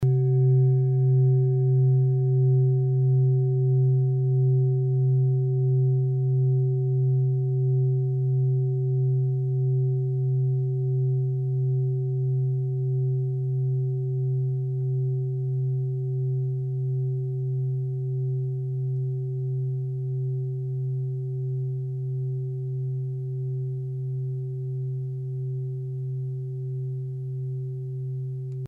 Tibet Klangschale Nr.38
Sie ist neu und wurde gezielt nach altem 7-Metalle-Rezept in Handarbeit gezogen und gehämmert.
Hörprobe der Klangschale
(Ermittelt mit dem Filzklöppel)
Klangschalen-Gewicht: 2000g
Klangschalen-Öffnung: 25,8cm
klangschale-tibet-38.mp3